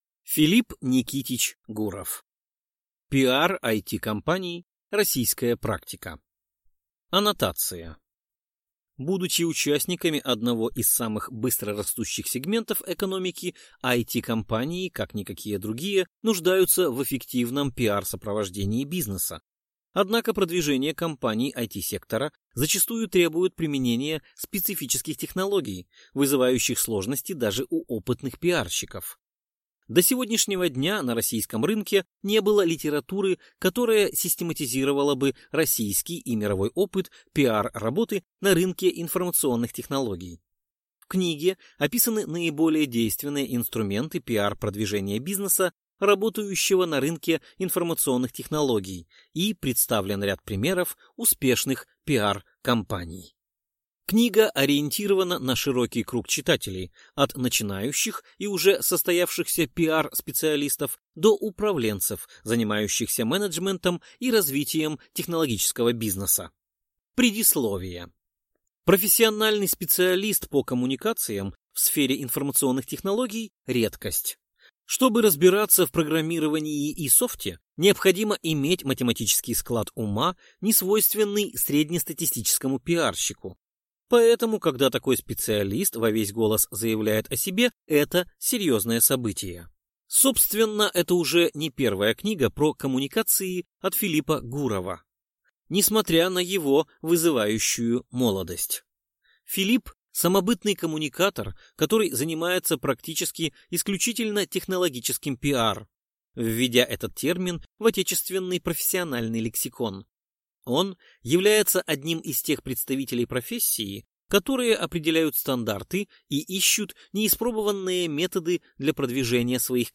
Аудиокнига PR IT-компаний: Российская практика | Библиотека аудиокниг